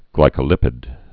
(glīkə-lĭpĭd)